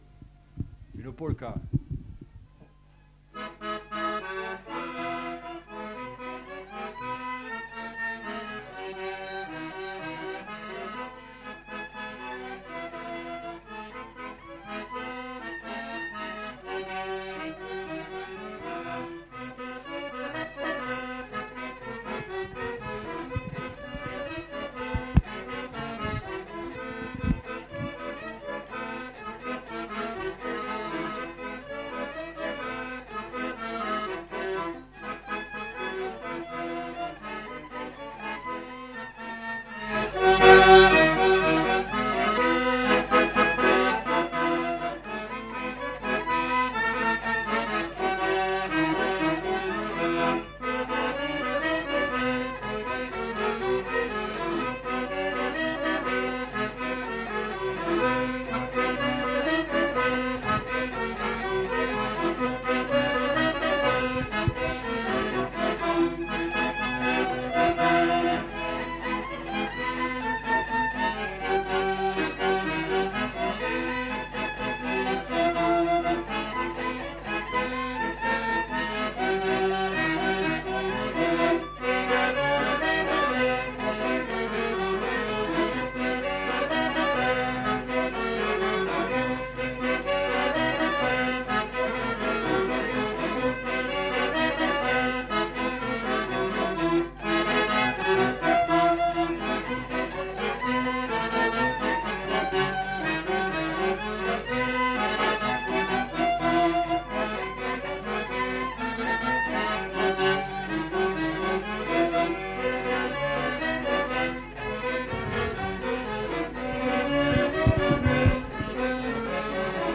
Répertoire de danses des Petites-Landes interprété au violon et à l'accordéon chromatique
enquêtes sonores
Polka